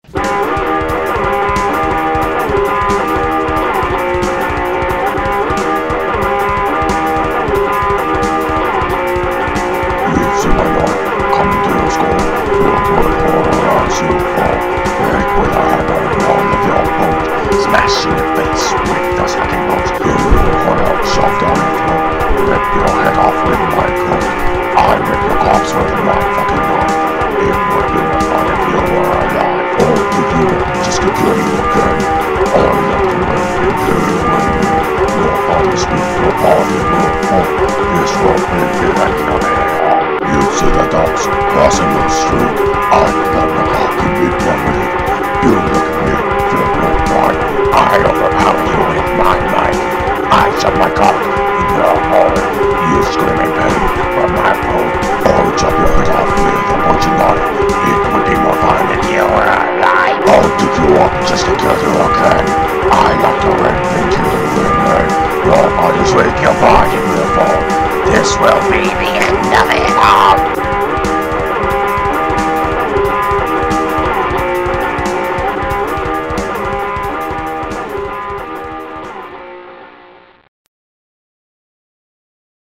All My Love (New Version)- This is my new version of "All My Love", as you can see. I finally got around to making it and I must say that I'm very impressed by the sound quality of it, though the song itself was not changed at all.